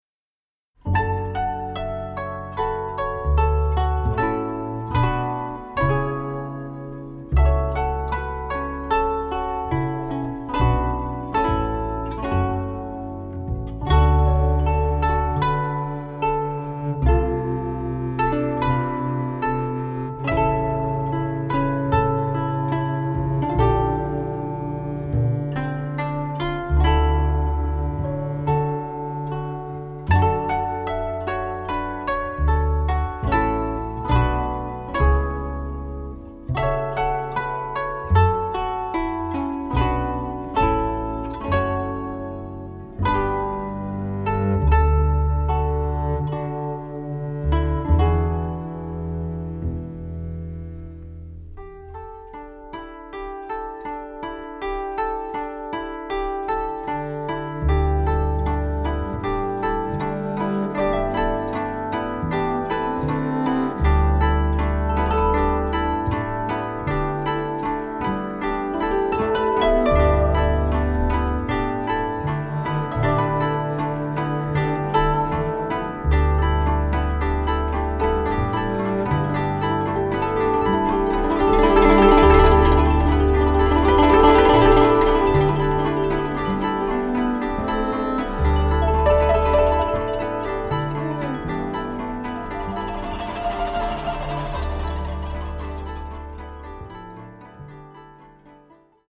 This is the third album of the duet- contrabass and Koto.
Please give yourself to the comfortable sound stories.